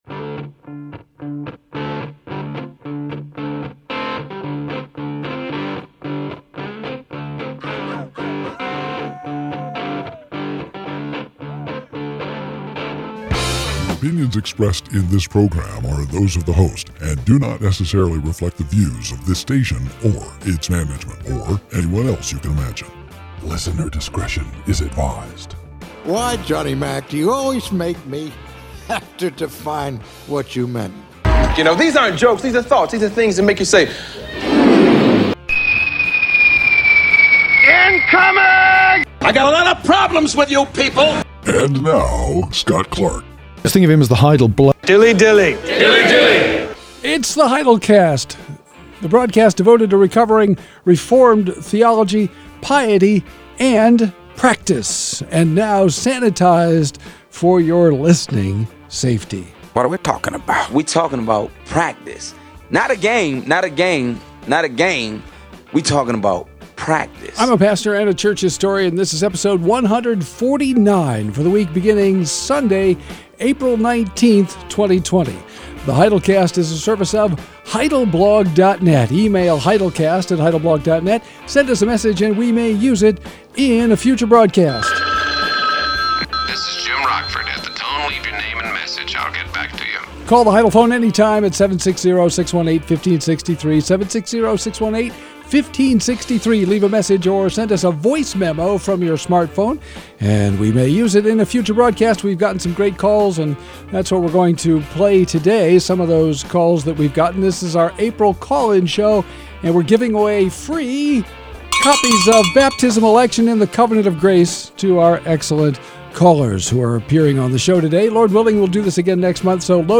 Now sanitized for your listening safety, the Heidelcast is back with episode 149 in which we take questions from Lancaster, PA, Houston, TX, Cork, Ireland, and Belfast, UK.